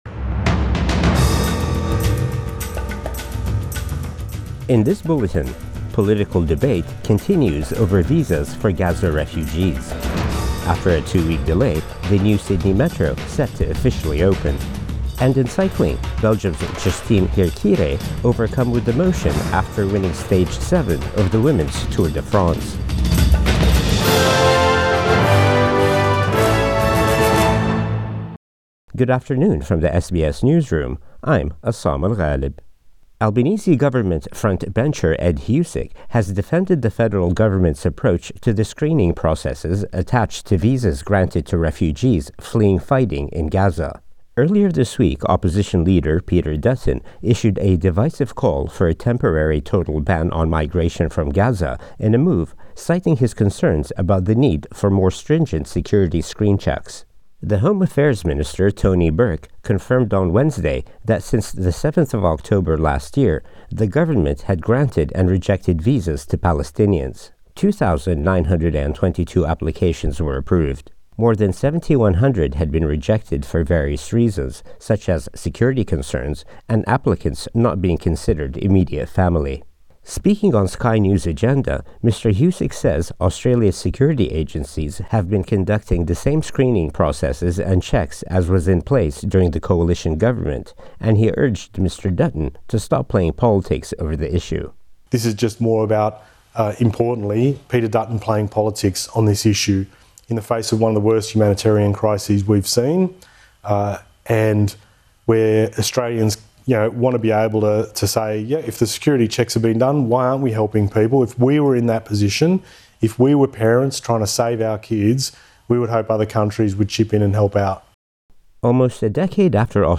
Midday News Bulletin 18 August 2024